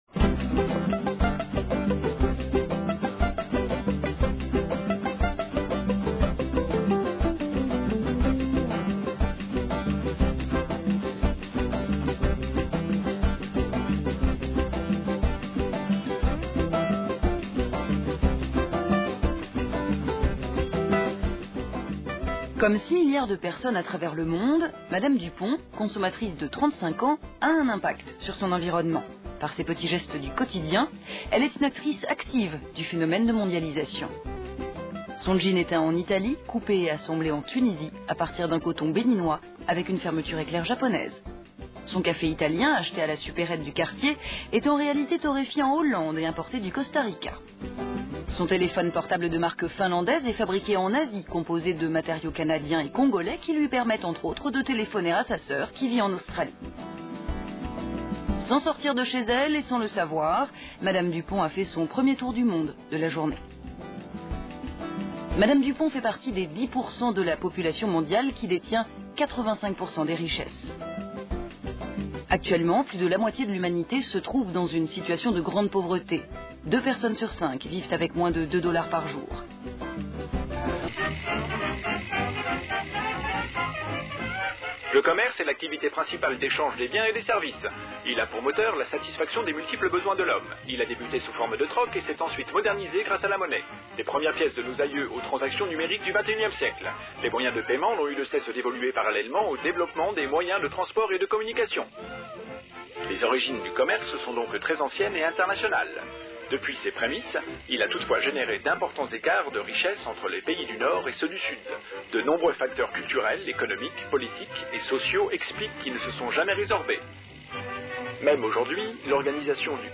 Ce documentaire propose, après une introduction au commerce équitable (historique, mécanismes,…), de découvrir la filière café à partir de l’exemple de la coopérative costaricienne Coocafé.